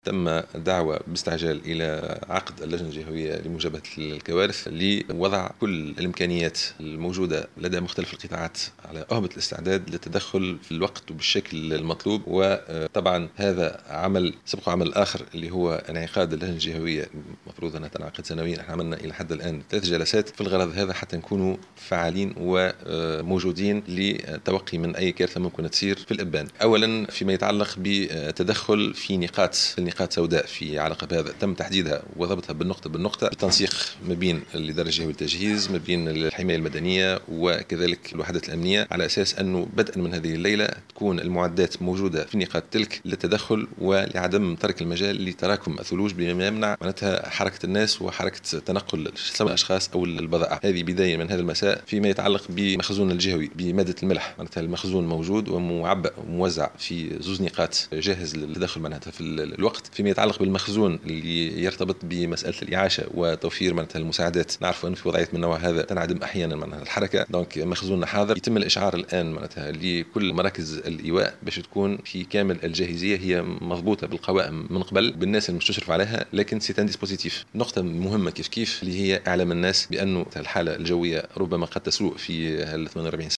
أكد والي سليانة سليم التيساوي في تصريح لمراسل الجوهرة اف ام اليوم الجمعة 15 جانفي...